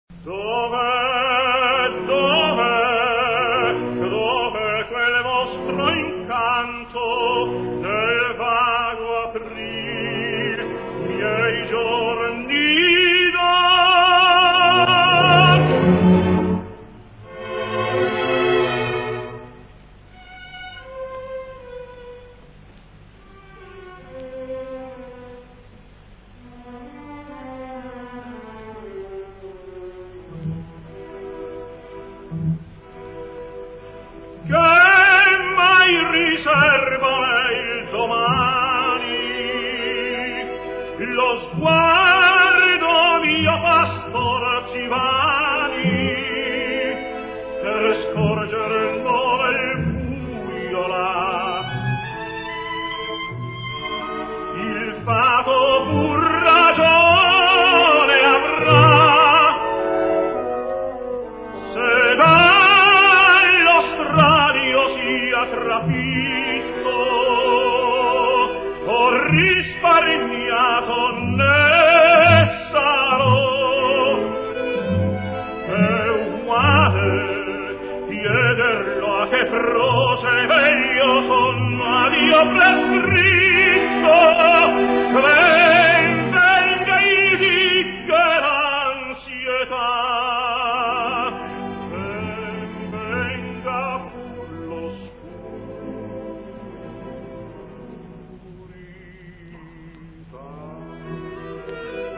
Mantengo la promessa di farvi ascoltare Giuseppe Di Stefano nel'aria di Lenski
только лишь фрагментик очень редкой записи Rai 1960 года и потом ещё один кусочек из Мефистофеля